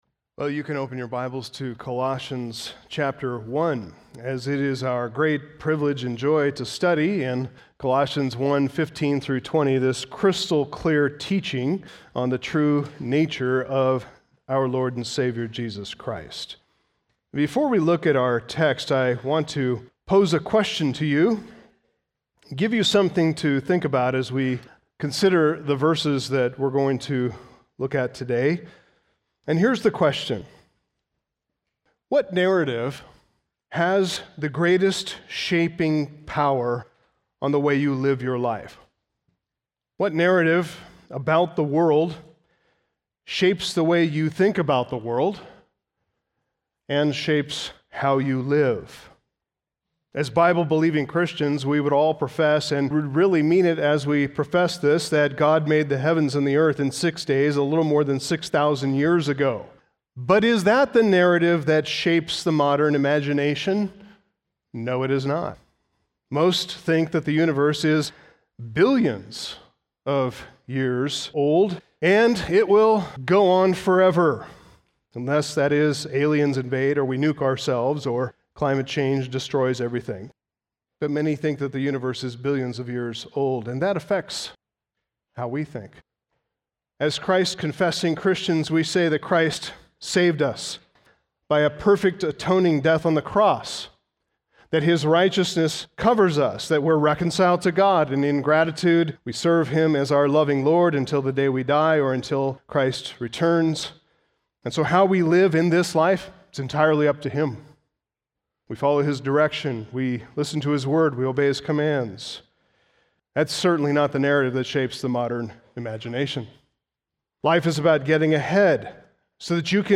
Sermons , Sunday Morning